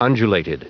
Prononciation du mot : undulated